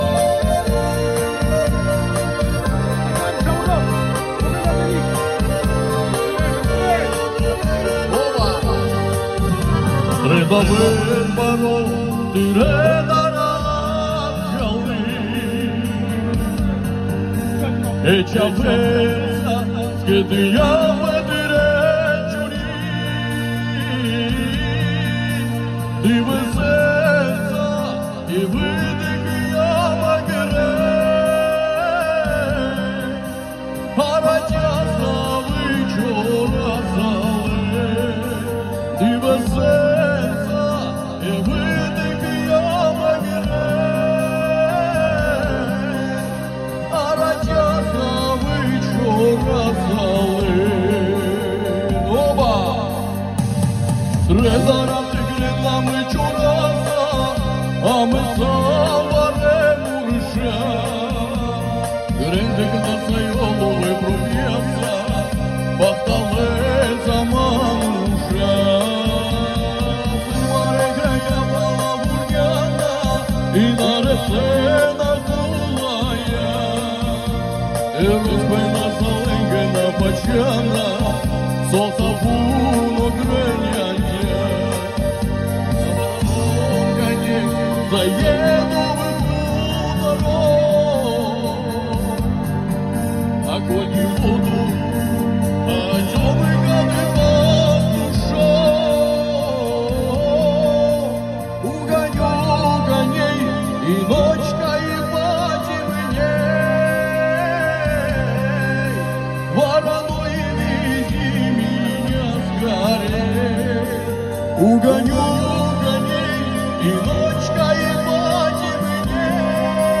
Цыганские голоса и голоса армян просто 🔥 Огонь!!!!